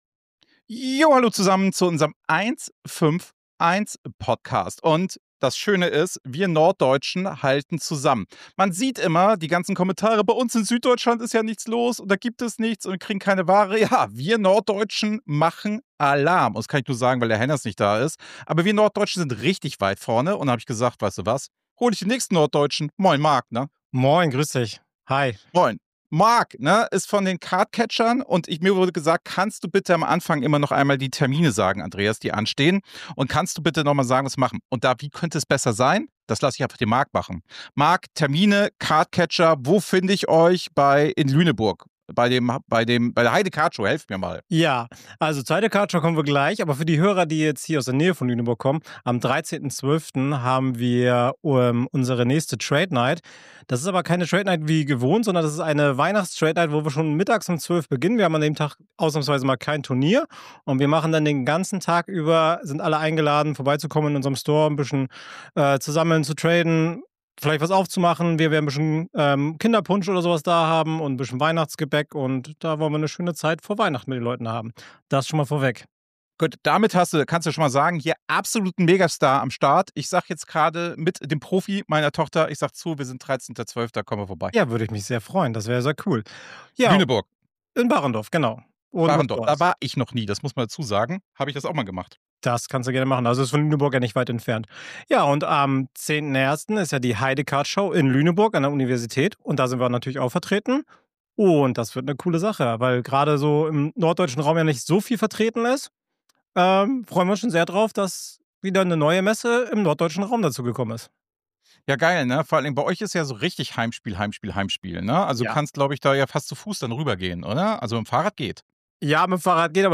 Das Ganze ohne Filter, ohne PR-Sprech, ohne Zensur. Ein erwachsener Deep Dive in die Realität unseres Hobbys.